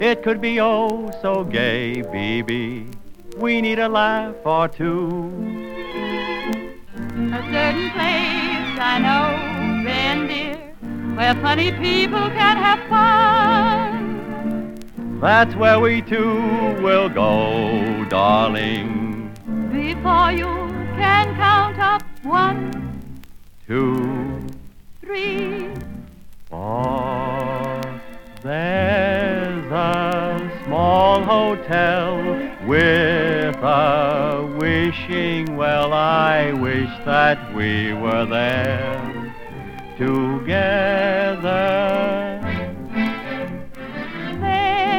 Pop, Vocal, Staege & Screen　UK　12inchレコード　33rpm　Mono